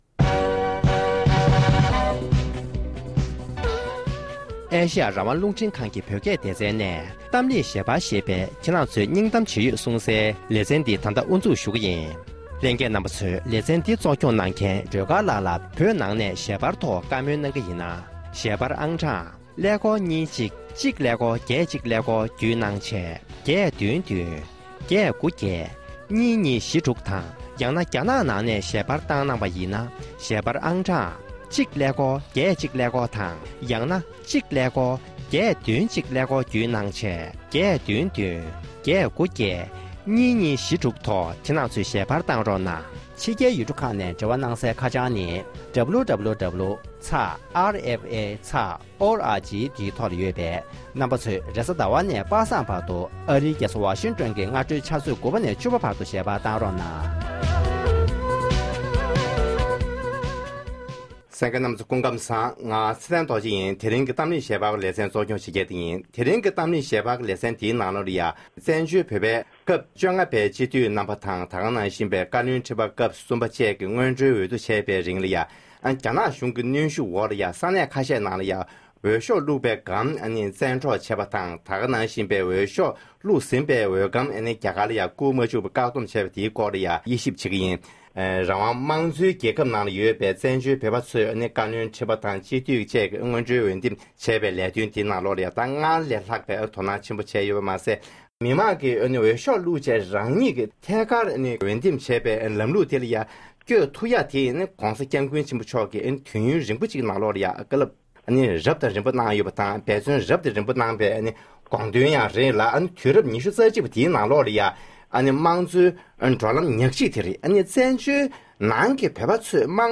ཨ་རི་དང༌།ཡོ་རོབ།དེ་བཞིན་ཨེ་ཤེ་ཡ་བཅས་ཀྱི་ནང་ཡོད་པའི་མི་སྣ་གསུམ་གྱི་ལྷན་དུ